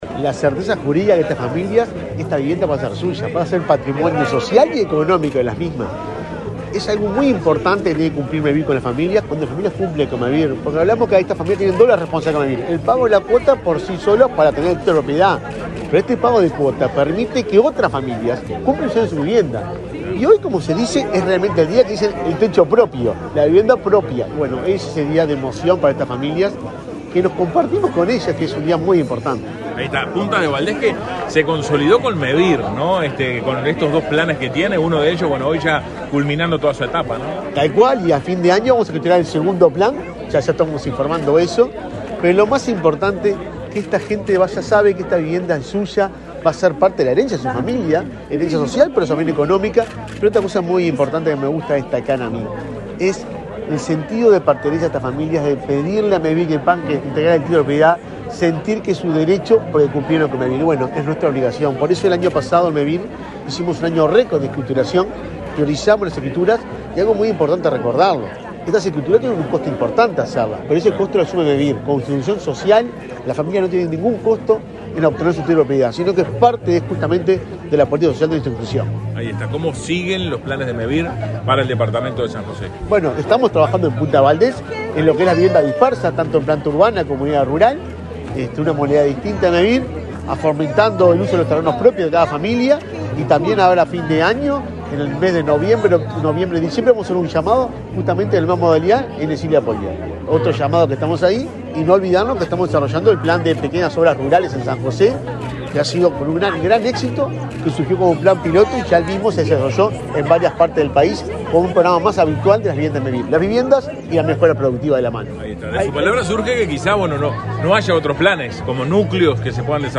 Declaraciones a la prensa del presidente de Mevir, Juan Pablo Delgado
Declaraciones a la prensa del presidente de Mevir, Juan Pablo Delgado 30/09/2022 Compartir Facebook X Copiar enlace WhatsApp LinkedIn Tras participar en el acto de escritura de 50 viviendas en la localidad de Punta de Valdez, en San José, este 30 de setiembre, el presidente de Mevir realizó declaraciones a la prensa.